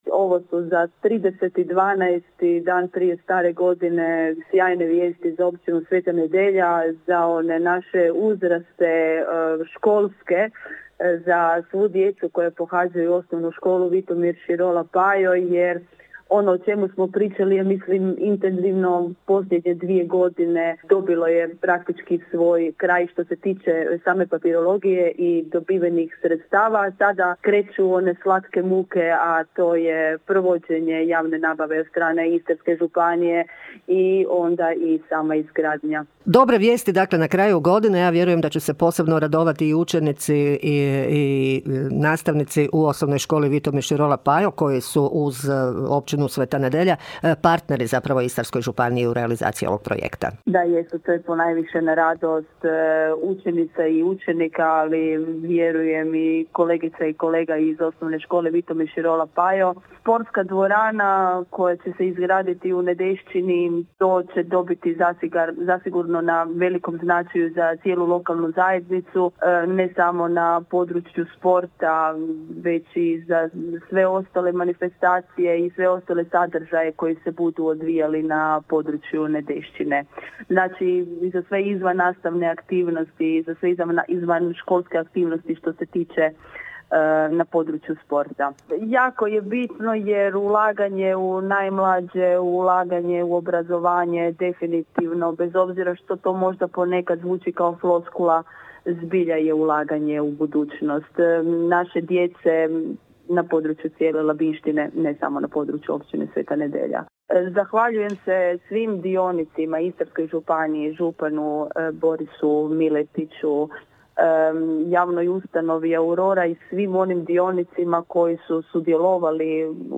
Vijest o odobrenju bespovratnih sredstava posebno je razveselila i načelnicu Općine Sveta Nedjelja Irenu Franković, koja je izrazila veliko zadovoljstvo realizacijom ovog važnog projekta. (
ton – Irene Franković).